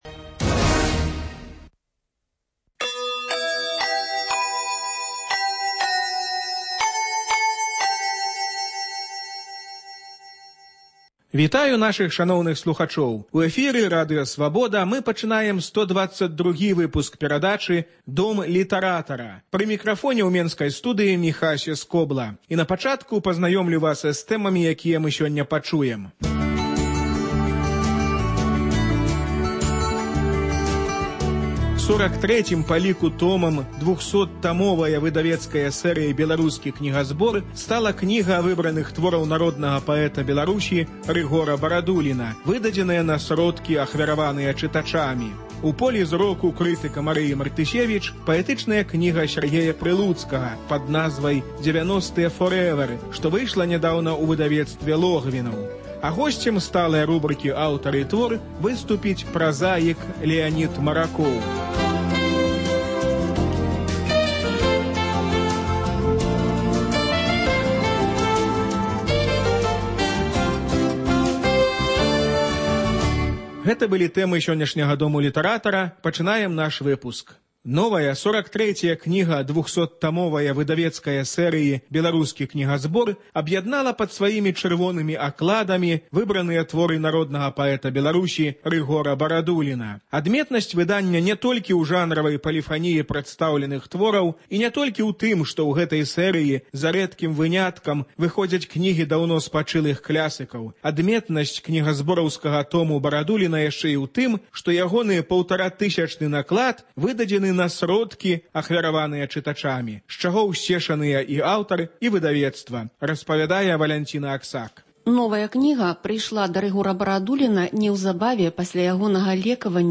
Літаратурны агляд з удзелам Рыгора Барадуліна